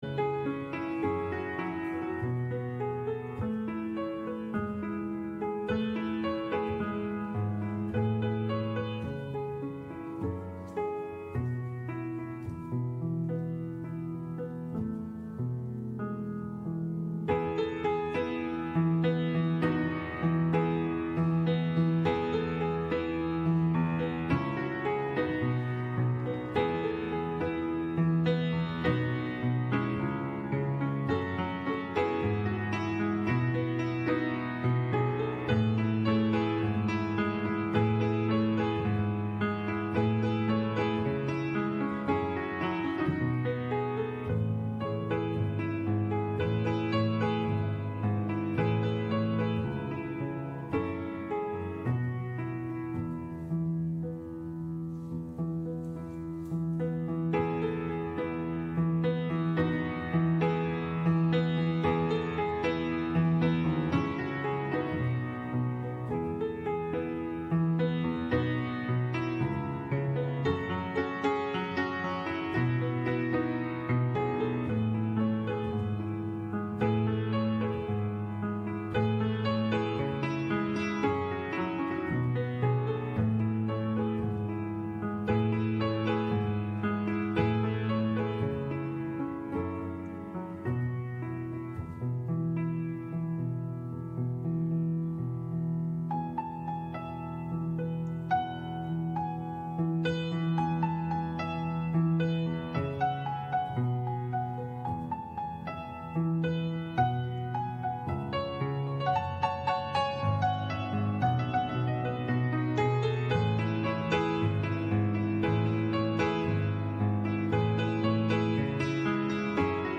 Gottesdienst am 14. Januar 2024 aus der Christuskirche Altona